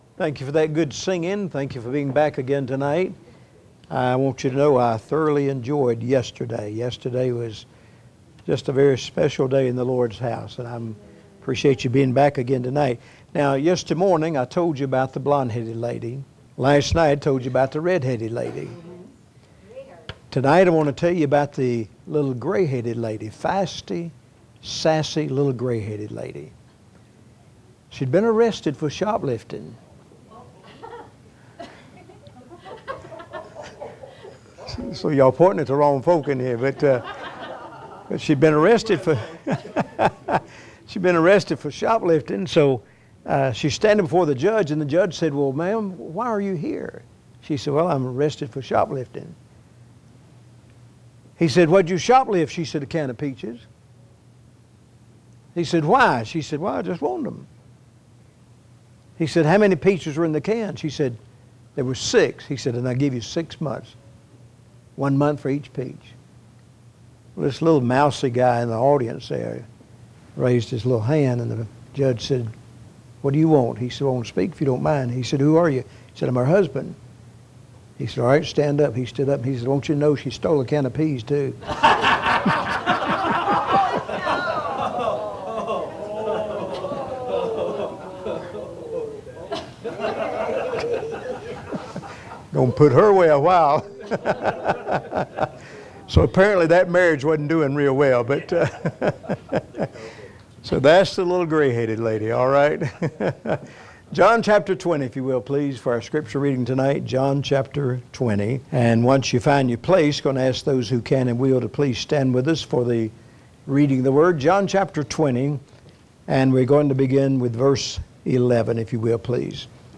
A message from the series "Out of Series."